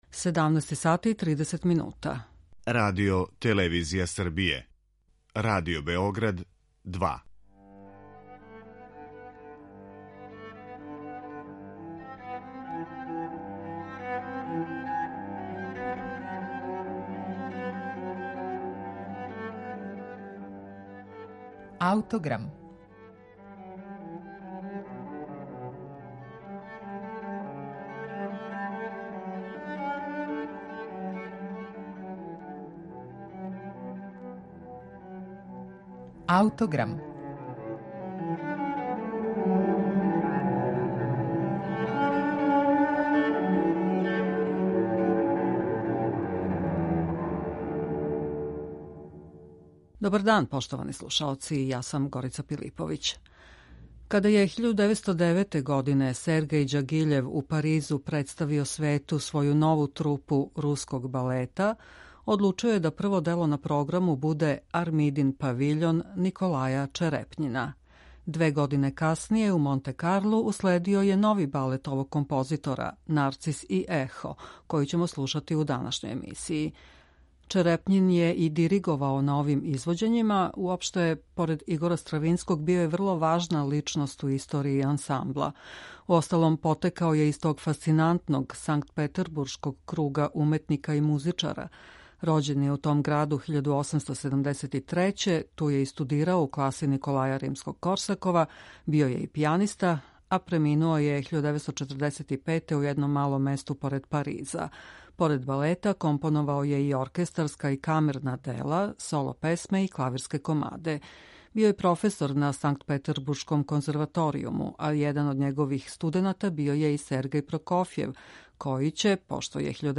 Балетска музика Николаја Черепњина